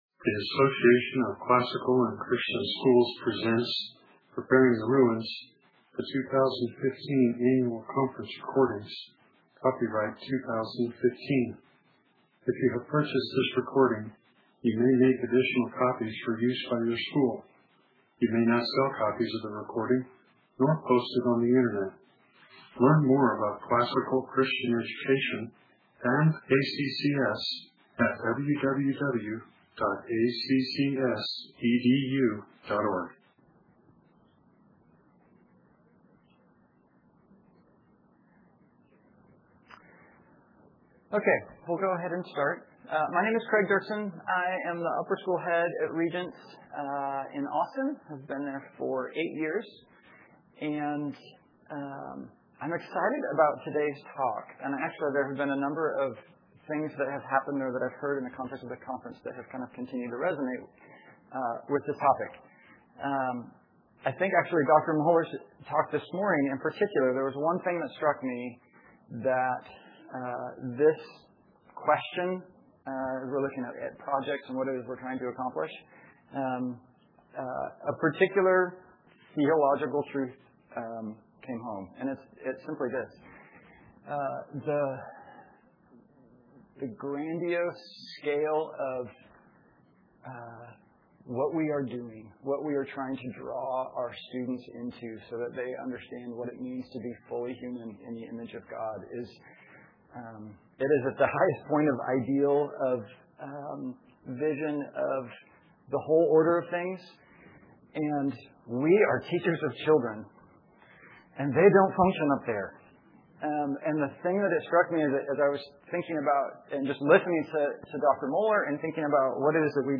2015 Workshop Talk | 0:52:15 | All Grade Levels, General Classroom
Additional Materials The Association of Classical & Christian Schools presents Repairing the Ruins, the ACCS annual conference, copyright ACCS.